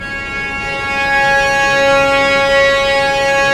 Index of /90_sSampleCDs/Roland - String Master Series/STR_Vcs Bow FX/STR_Vcs Sul Pont